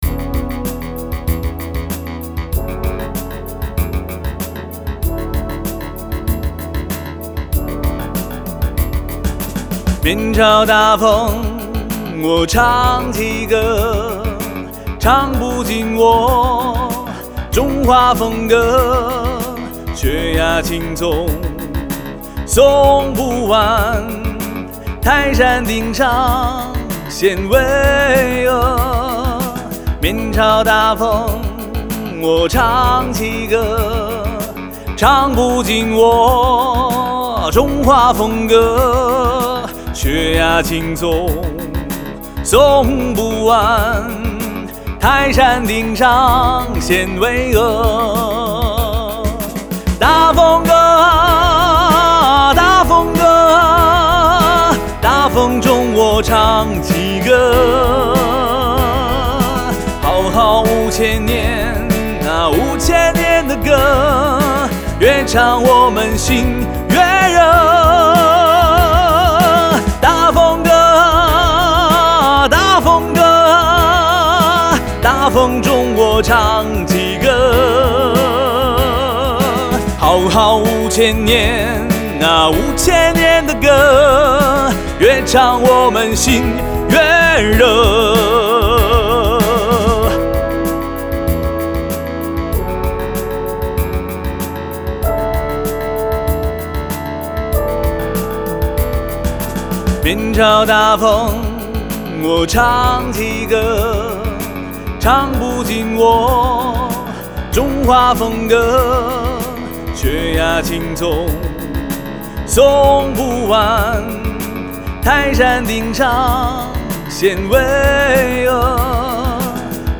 曲风：民谣